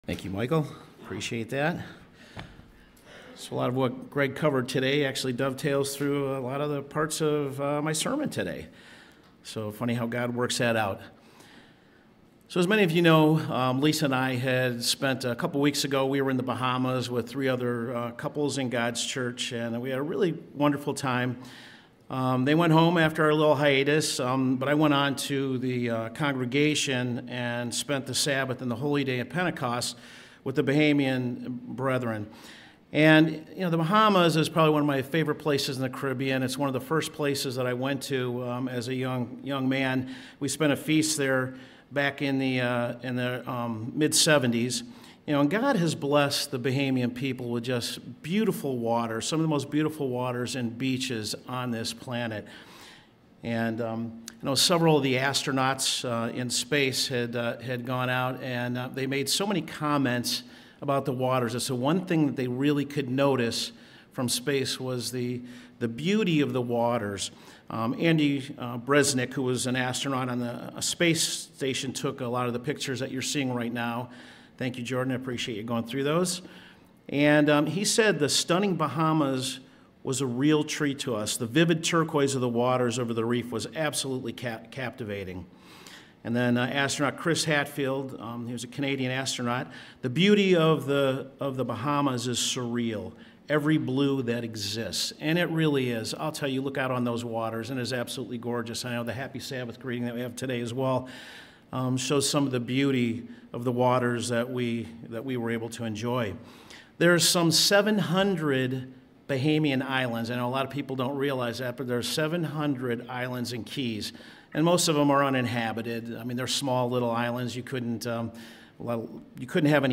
sermon_one_to_many_the_power_of_god.mp3